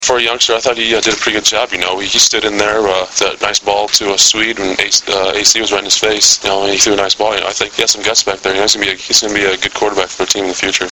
Press Conference Audio Links (Oct. 21)